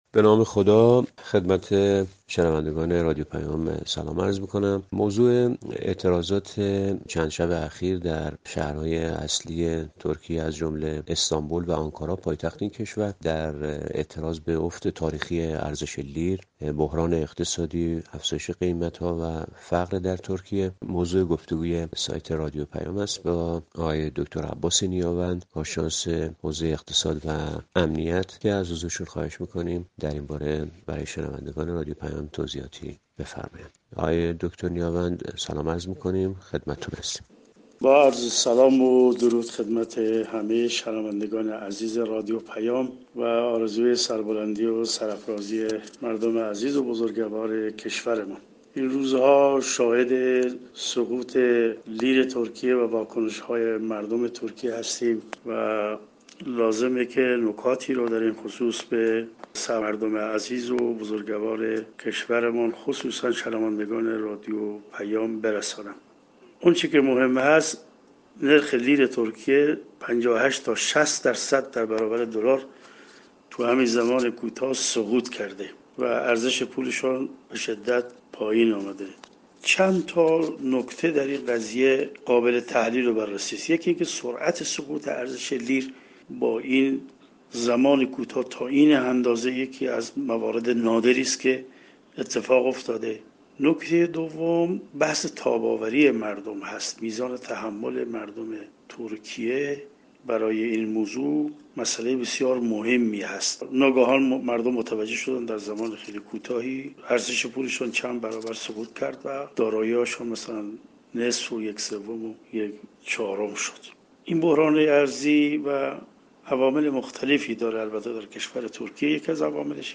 گفتگویی